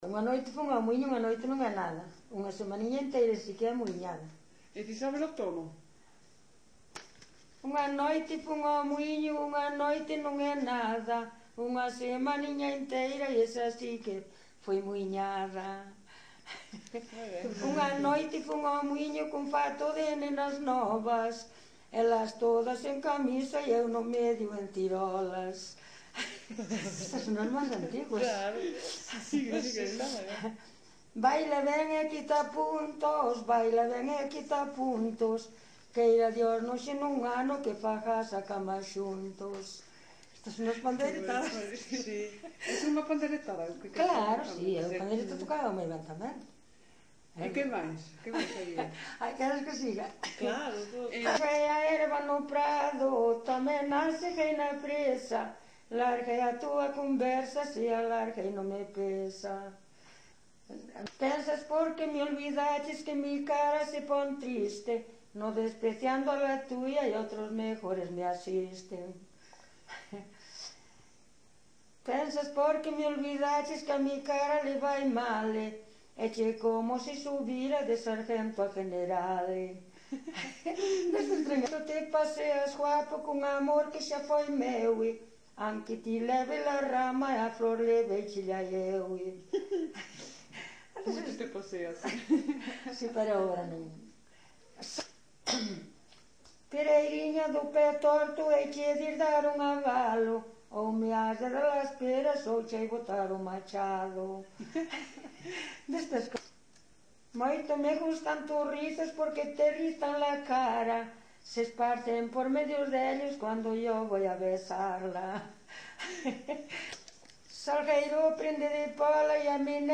1980 Concello: Vila de Cruces.